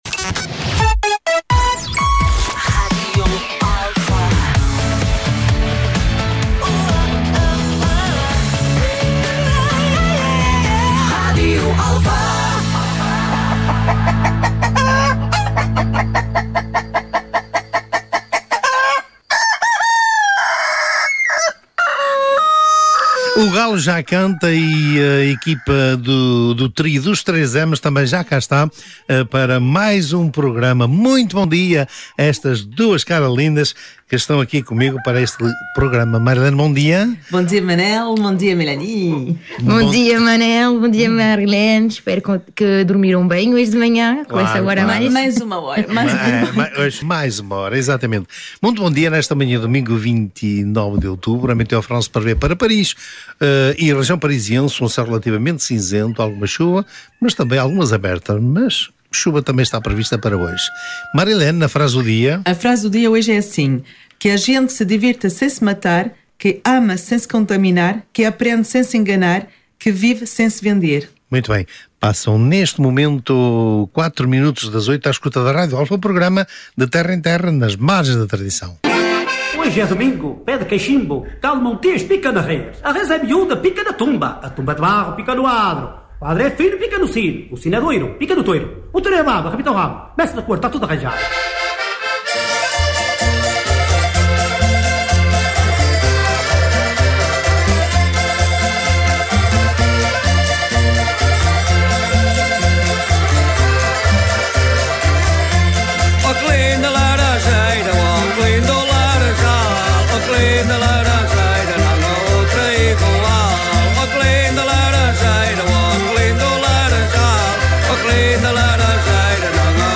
A música tradicional portuguesa e as tradições populares